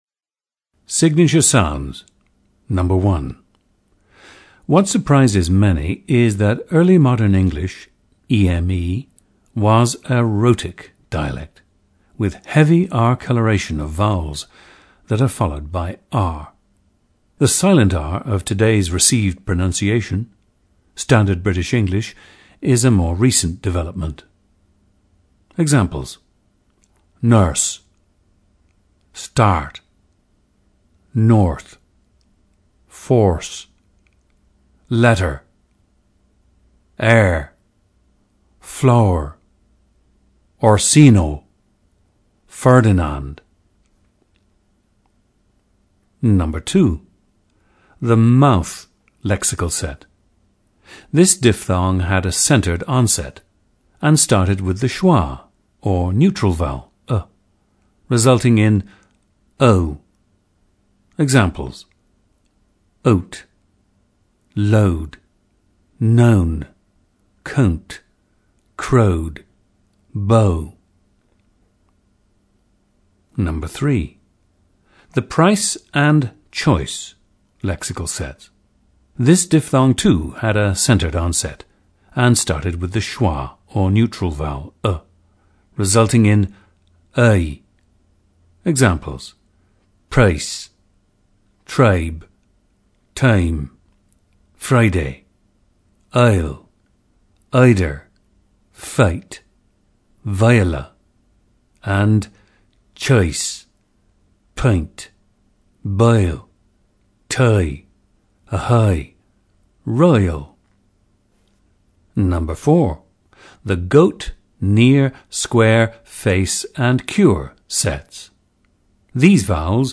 This diphthong, too, had a centered onset and started with the schwa, or neutral vowel ,[ə], resulting in [əɪ].